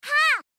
Kirby Saying Haa Efeito Sonoro: Soundboard Botão